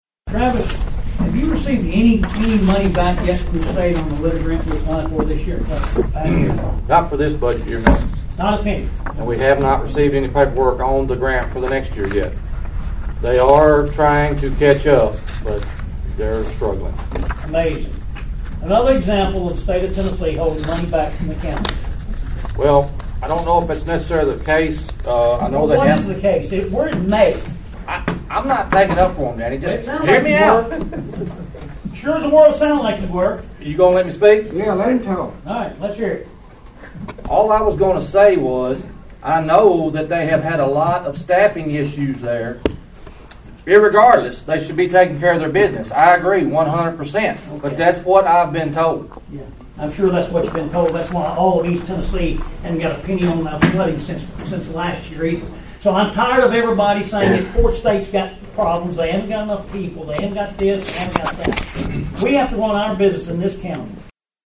The lack of timely funding to the Obion County Recycling Center drew sharp criticism at Friday’s County Commission meeting.